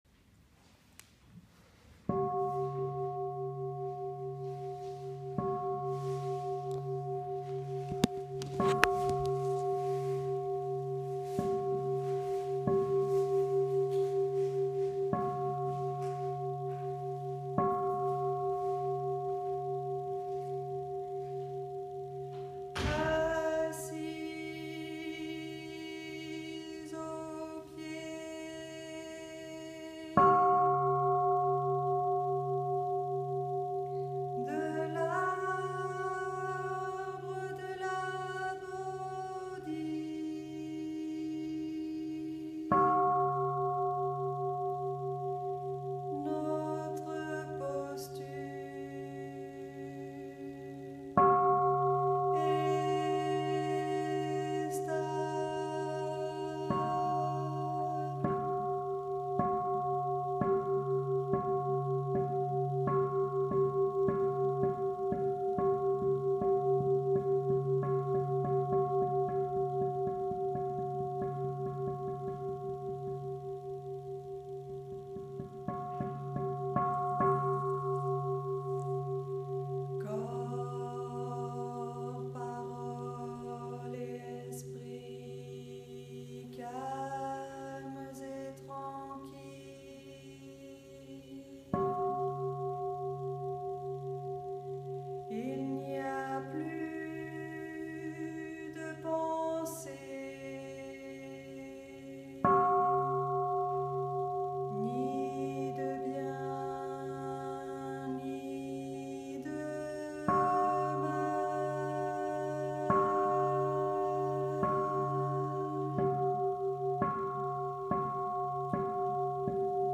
Chant d’introduction à la méditation assise du soir.